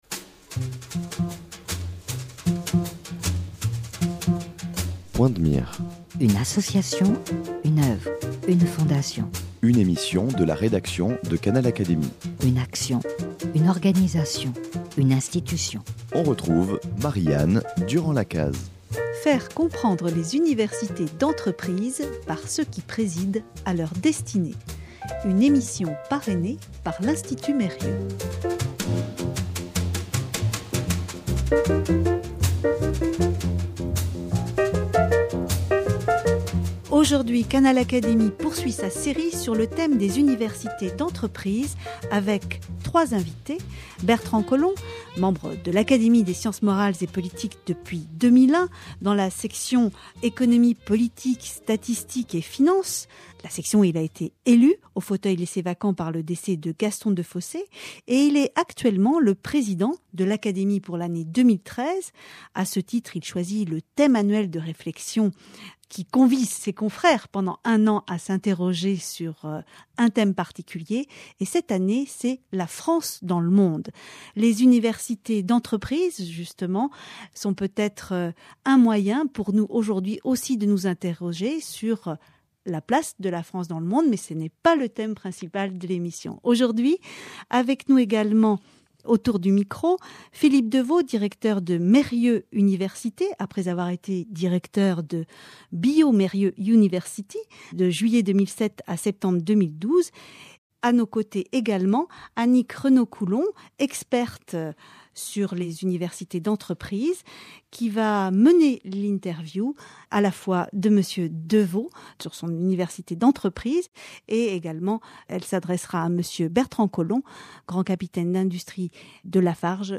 Le regard de trois experts.